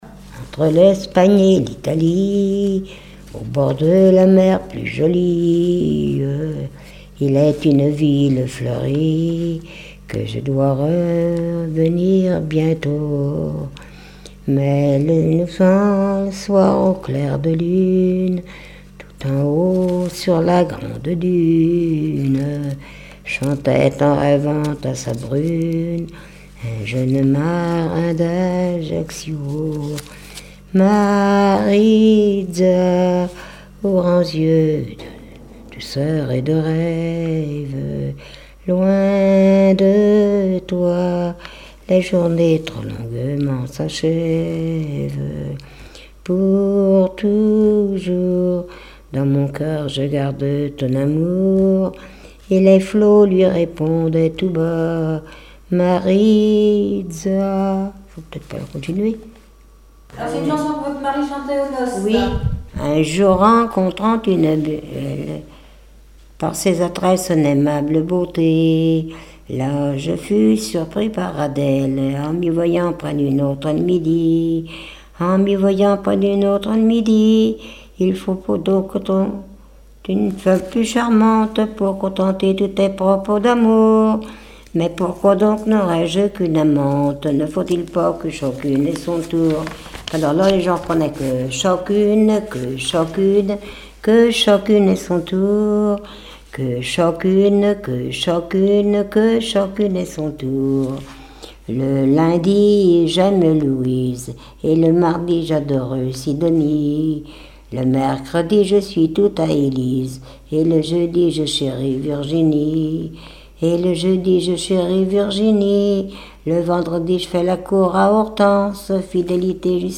chantée par des hommes aux noces
strophique
Répertoire de chansons populaires et traditionnelles
Pièce musicale inédite